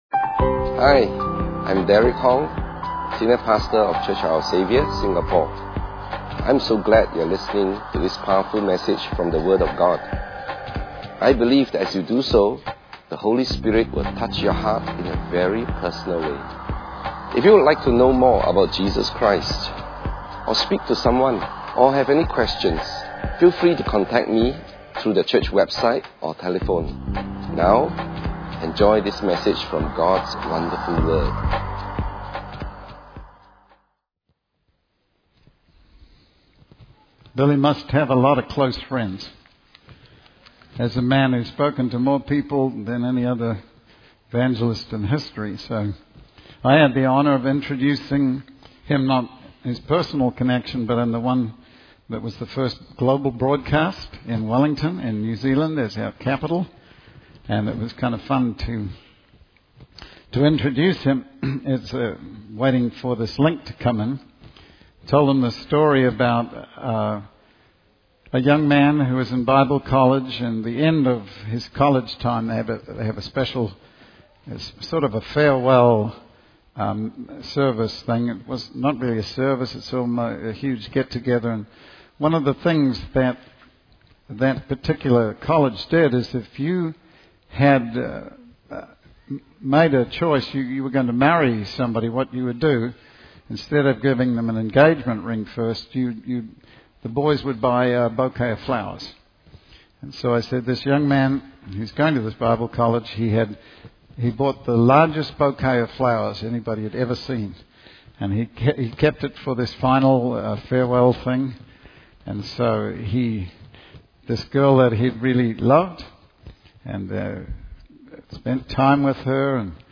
In this sermon, the speaker discusses three parables from the Bible: the parable of the lost sheep, the parable of the lost coin, and the parable of the prodigal son. The speaker emphasizes that these parables are not just stories, but descriptions of reality and God's laws. He highlights the importance of understanding the cost and effort God put into finding and saving those who were lost.